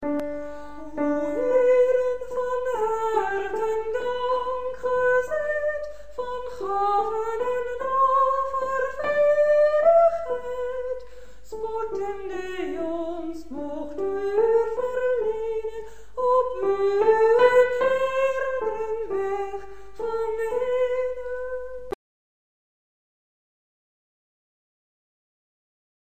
ingezongen in huiselijke kring